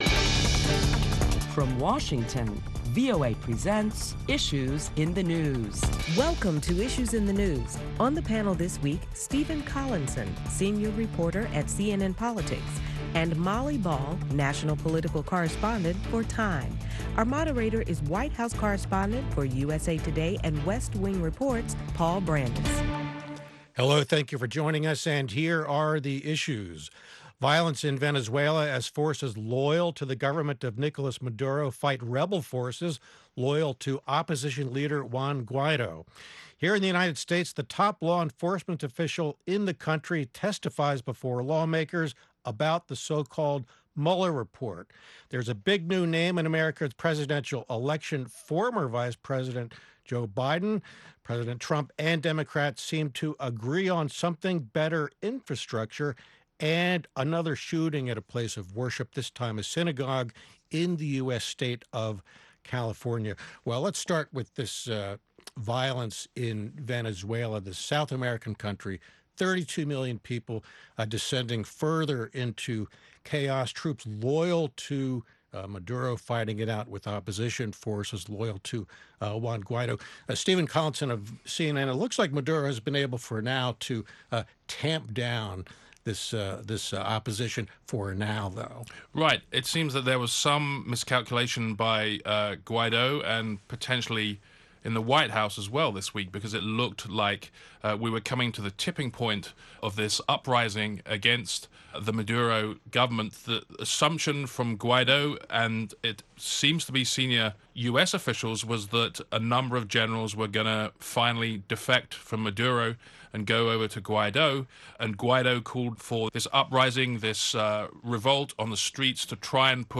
Listen to our panel of prominent Washington journalists as they deliberate the week's headlines including Attorney General William Barr’s refusal to testify to House after a contentious Senate hearing, and the latest on violent clashes in Venezuela.